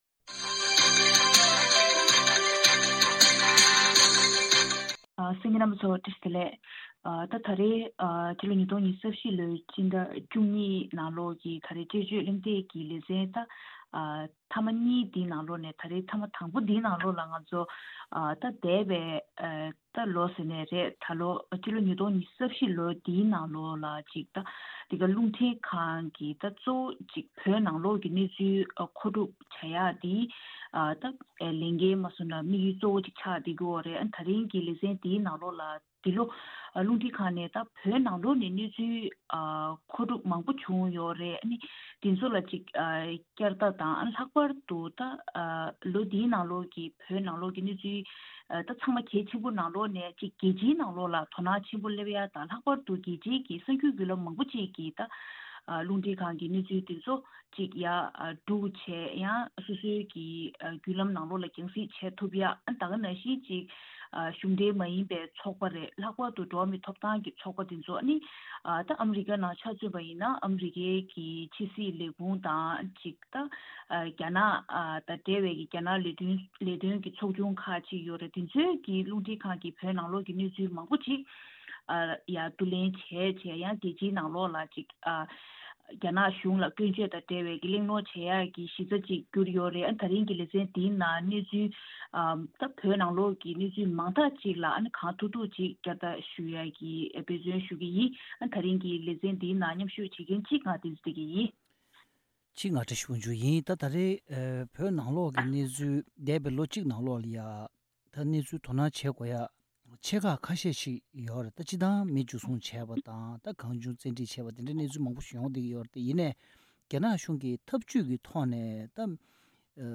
འདས་བའི་ལོ་གཅིག་གི་བོད་ནང་གི་གནས་ཚུལ་ལ་བསྐྱར་ལྟ་དཔྱད་གླེང་ཞུས་པ།